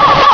bark3.ogg